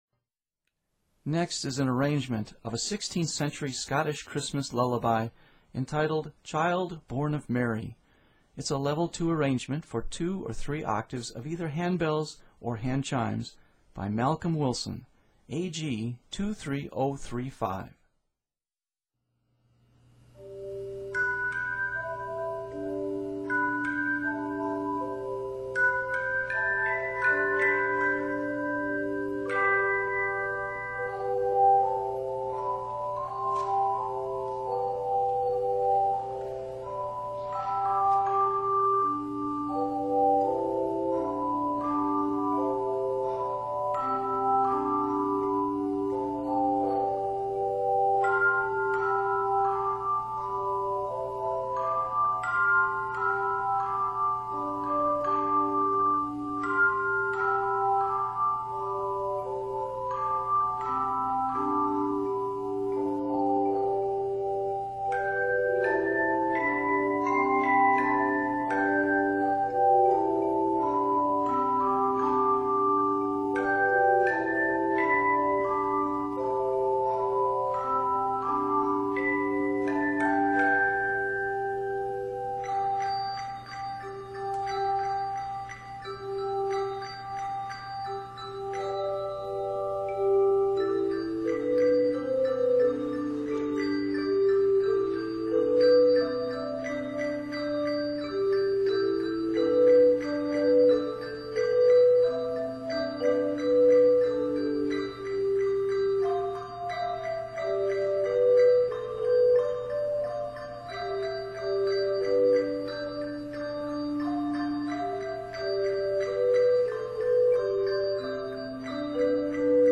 Scottish Christmas hymn
handbells or handchimes
Scored in G Major, it is 91 measures.